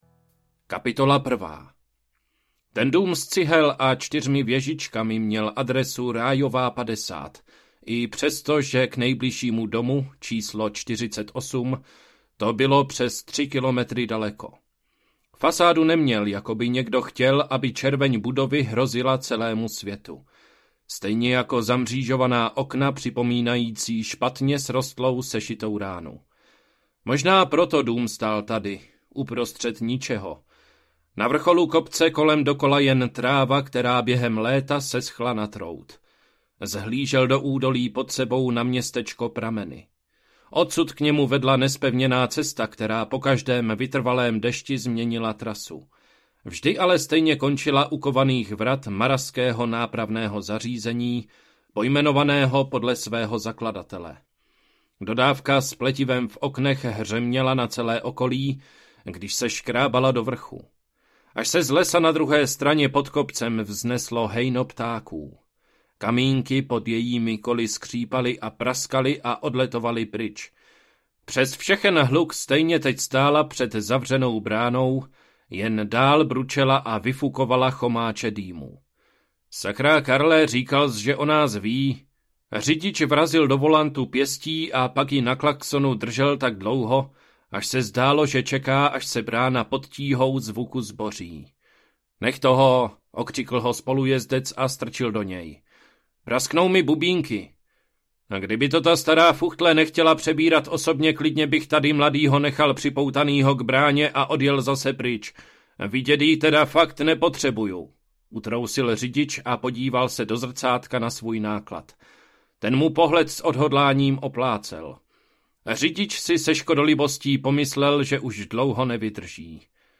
Audiokniha